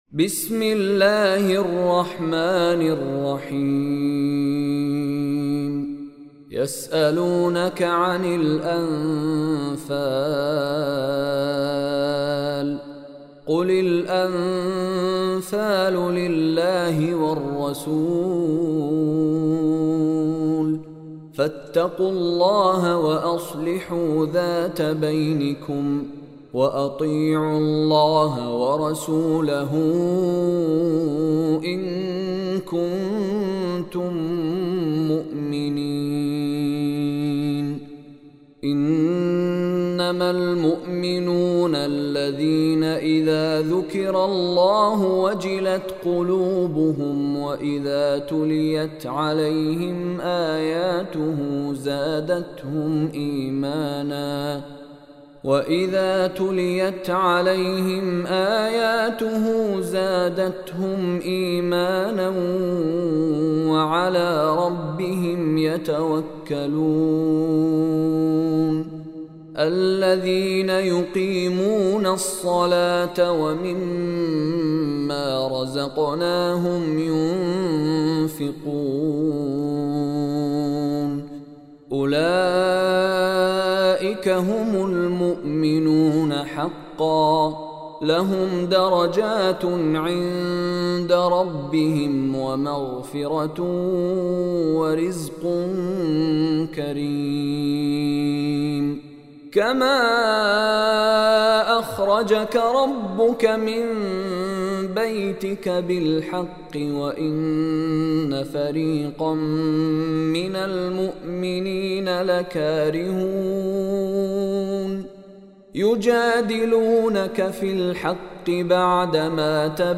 Surah Al-Anfal Tilawat by Sheikh Mishary Rashid
Surah Anfal is eight chapter of Holy Quran. Listen Surah Anfal Tilawat in the voice of Sheikh Mishary Rashid Alafasy.